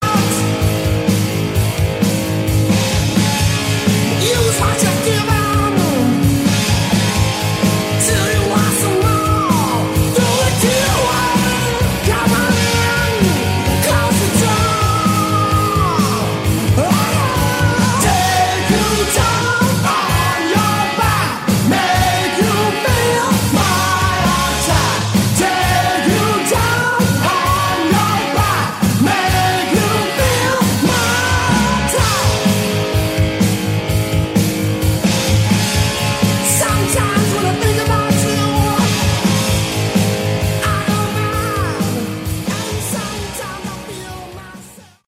Category: Hard Rock
vocals, guitar
bass
drums